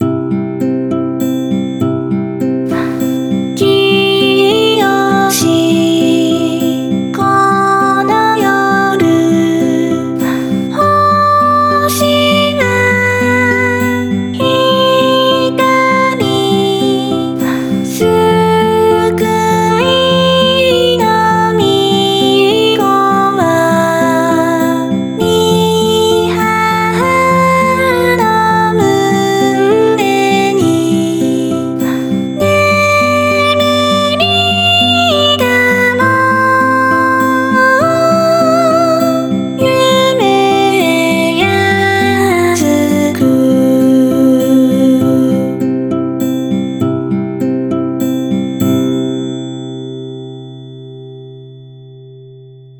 utau-kiyoshi-konoyoru.wav